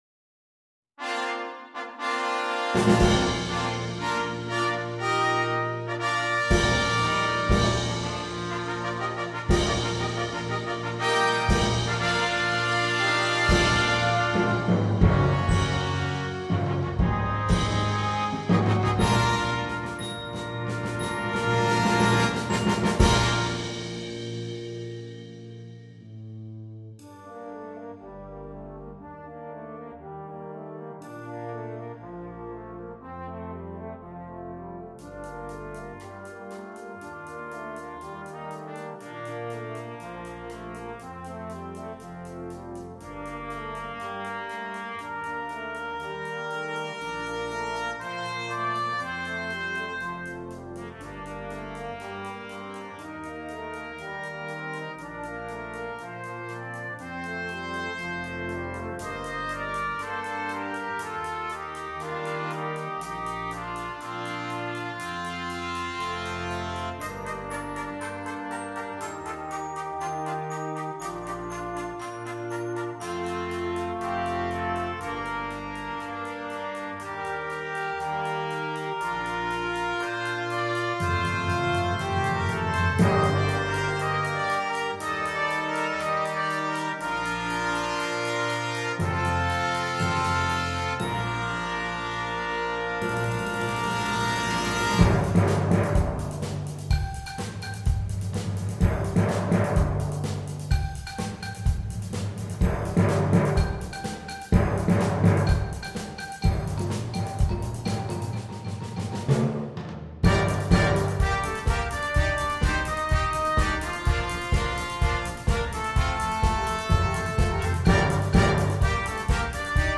Besetzung: Flex 5